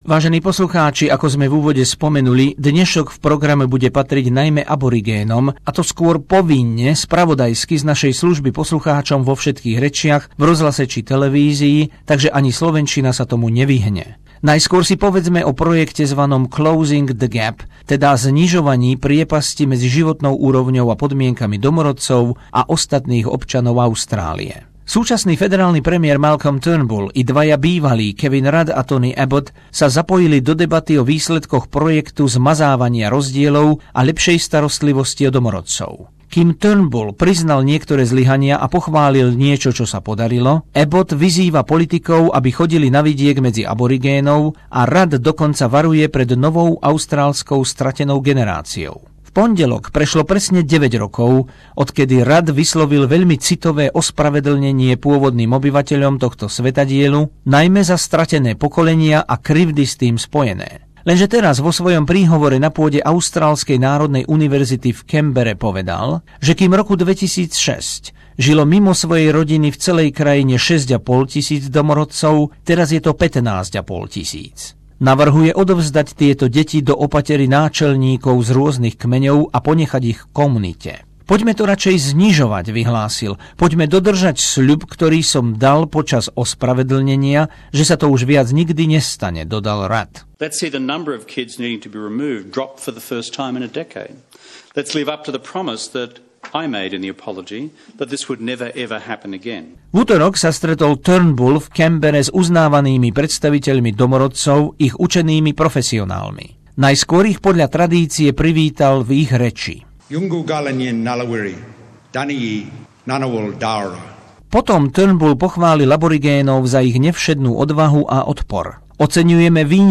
O pomoci domorodcom vo federálnom vládnom projekte hovoria o. i. aj traja premiéri, súčasný a bývalí , zo spravodajskej dielne SBS